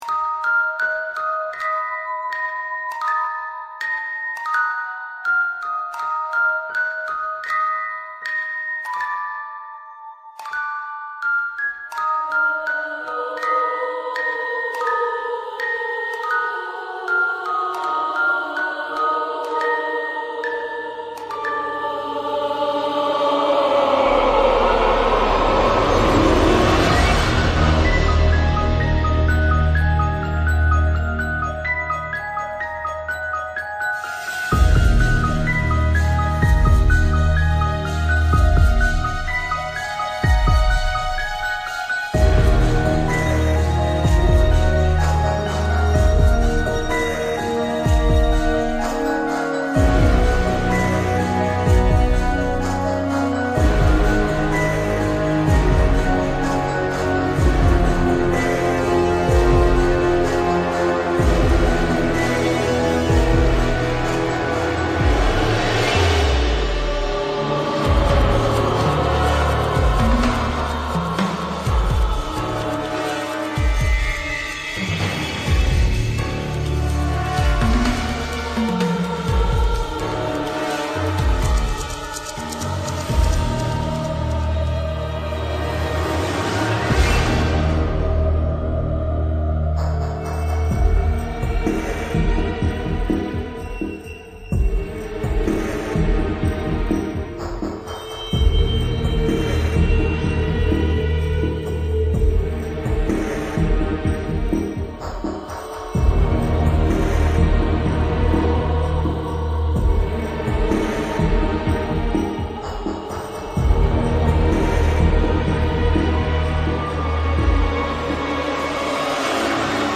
best-halloween-mega-remix-2015-creepy-anthem-new-dark-songs-1.mp3